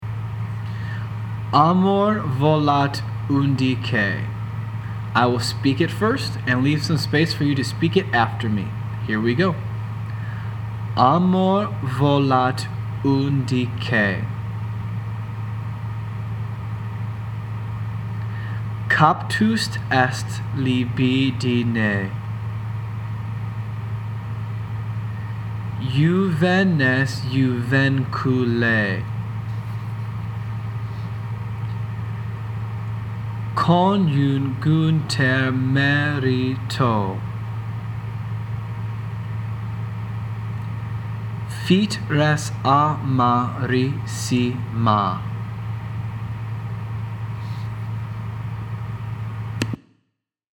Here are two pronunciation guides to help you practice these songs.
Amor-volat-undique-pronunciation.mp3